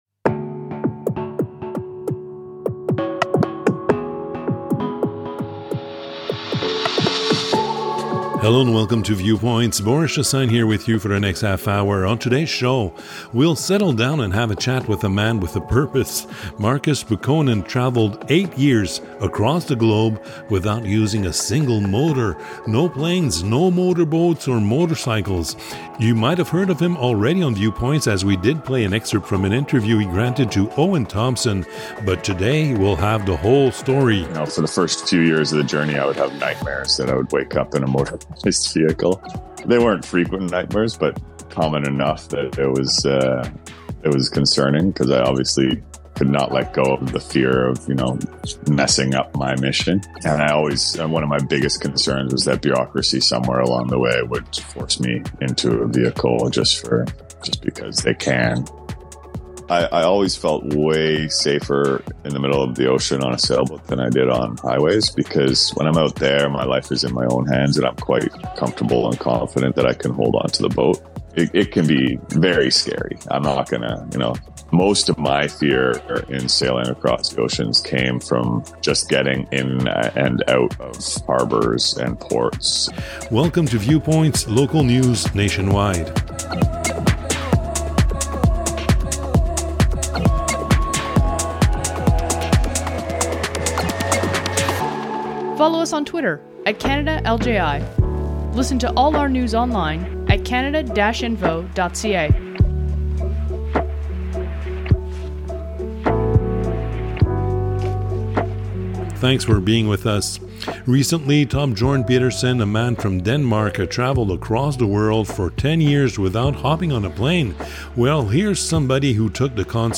On today’s show: We’ll settle down and have a chat with a man with a purpose.